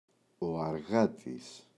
αργάτης, ο [a’rγatis]